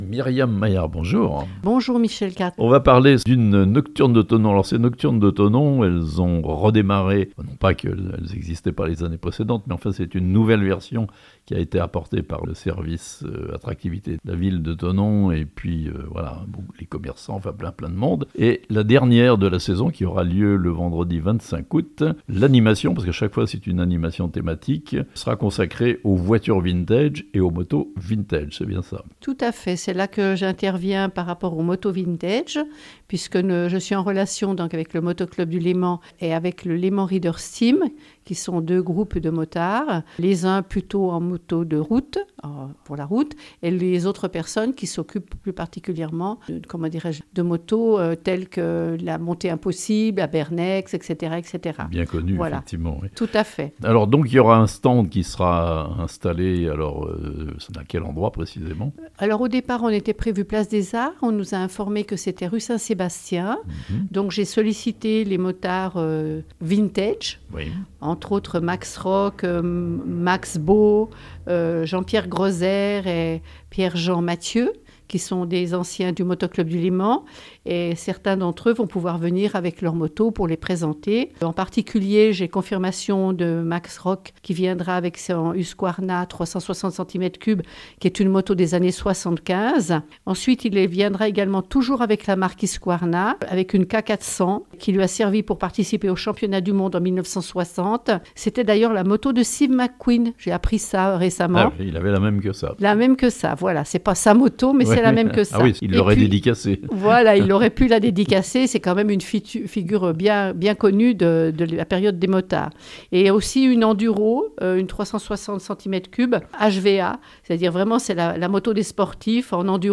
Thonon-les-Bains : une dernière nocturne sous le signe des voitures et motos vintage le 25 août (interview)